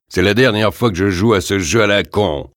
Tags: Duke Nukem sounds quotes Dukenukem Ultimate Ultime francais france repliques sons bruits voix phrases